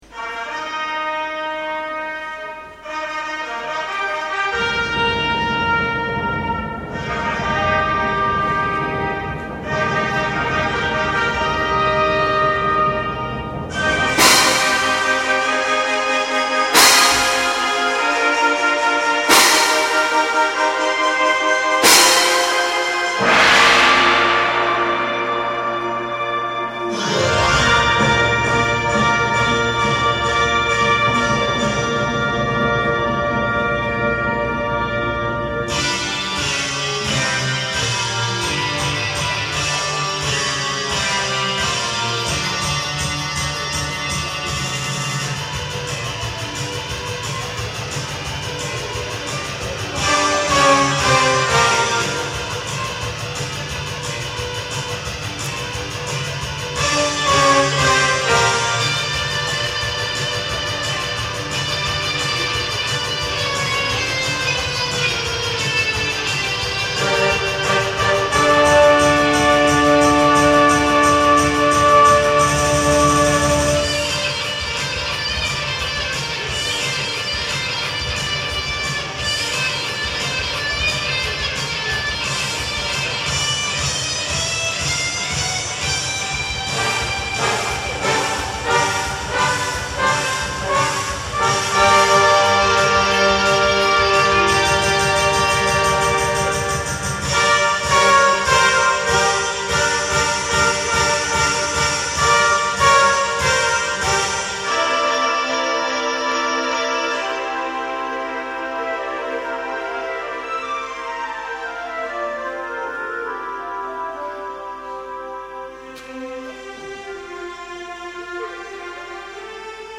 Musical mit Anspruch, romantisch, schwungvoll, abwechslungsreich
Das instrumentale